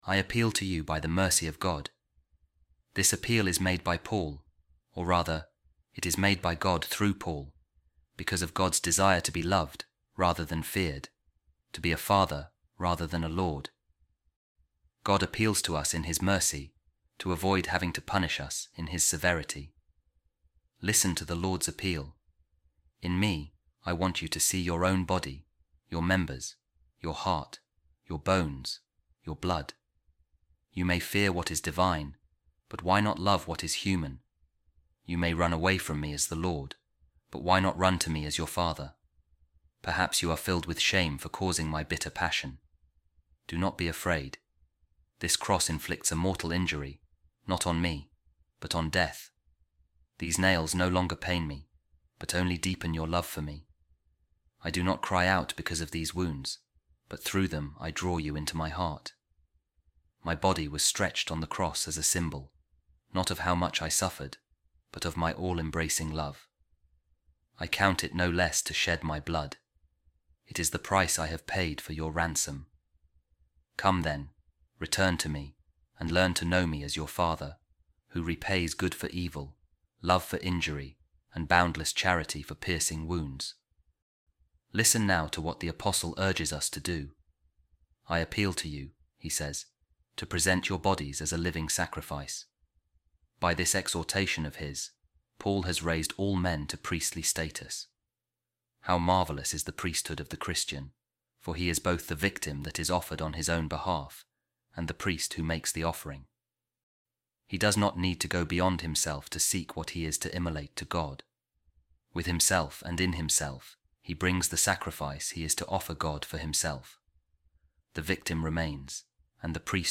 Office Of Readings | Eastertide Week 4, Tuesday | A Reading From The Sermons Of Saint Peter Chrysologus | By God’s Sacrifice And His Priest